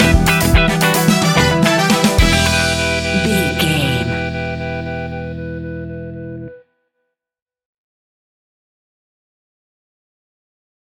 Aeolian/Minor
latin
salsa
uptempo
bass guitar
percussion
brass
saxophone
trumpet